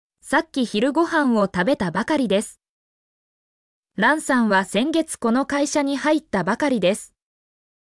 mp3-output-ttsfreedotcom-16_nGByuKu7.mp3